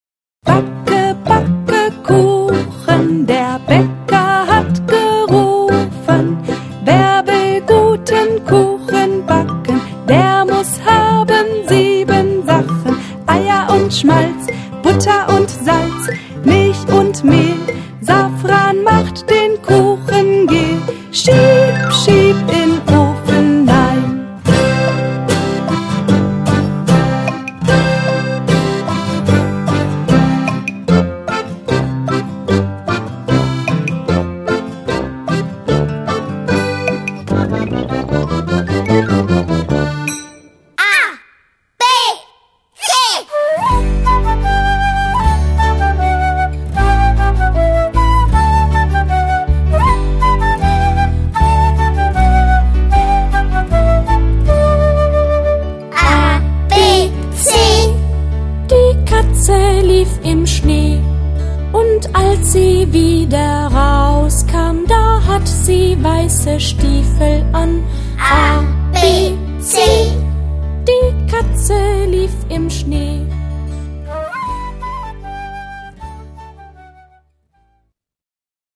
Ein fröhlicher Liederschatz für die ganze Familie.
Schlagworte Kinderlieder • Liedersammlung • Volkslieder